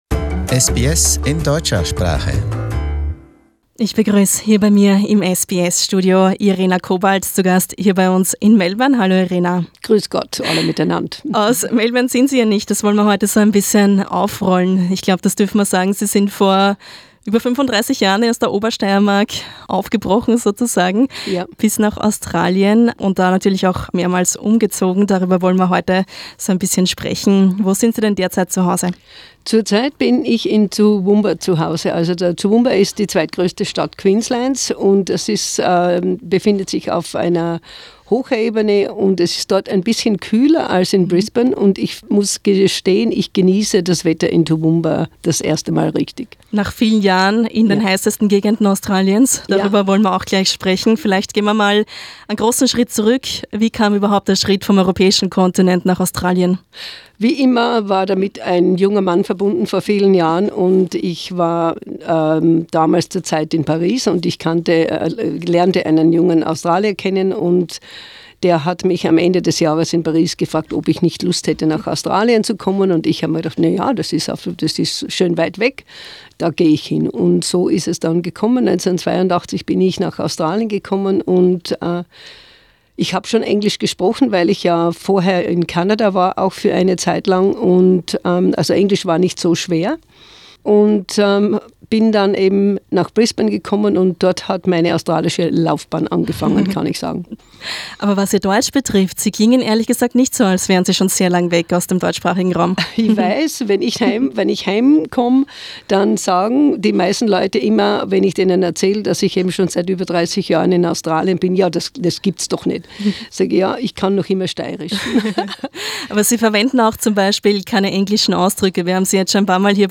In a personal interview, she shares her memories, particularly those of the ten year she had spent as a teacher in the NT, which she now considers 'the most beautiful time of her life'.